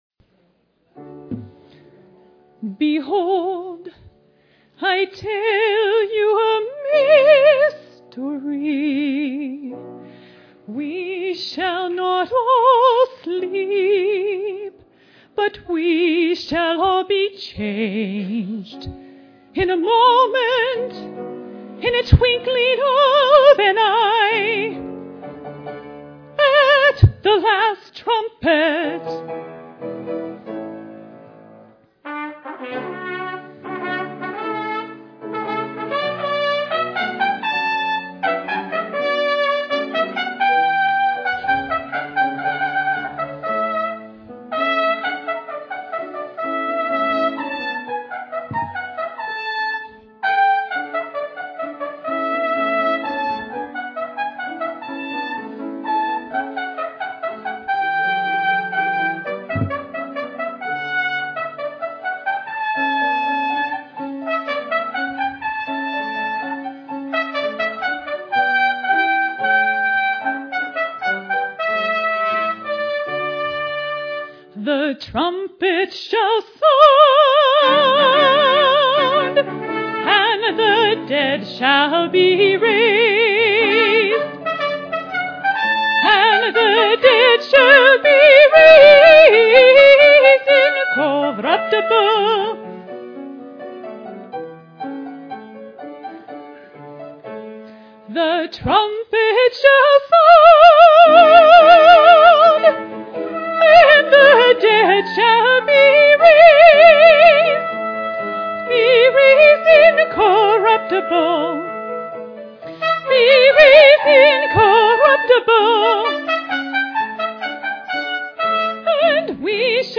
Copyright 2000 Lippencott Music.overture, Performed by Alturas Community Orchestra.
piano solos